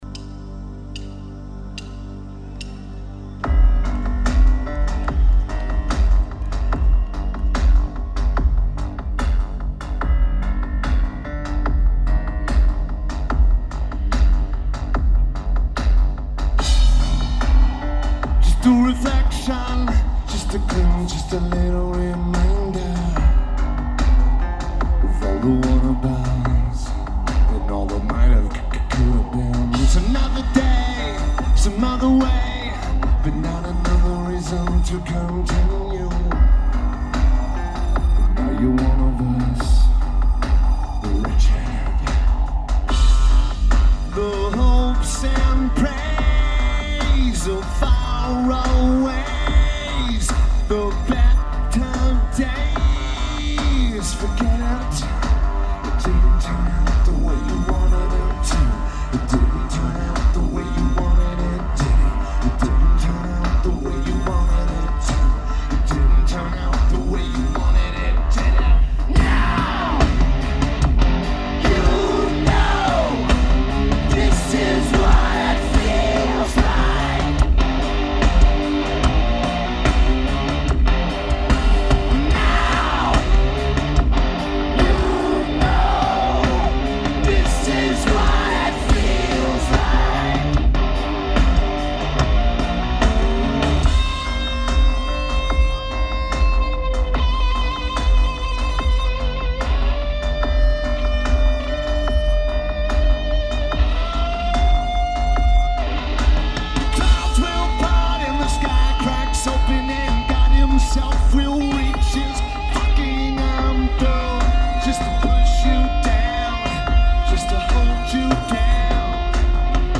Heineken Music Hall
Lineage: Audio - AUD (Schoeps CCM41V + Maranta PMD661)